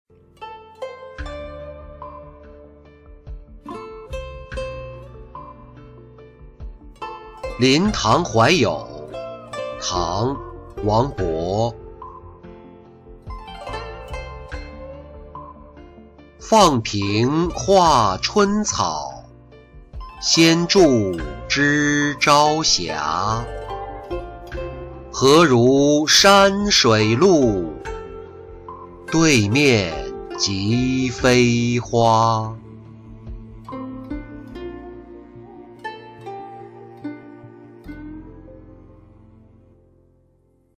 赠花卿-音频朗读